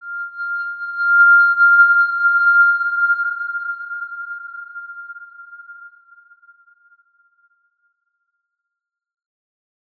X_Windwistle-F5-ff.wav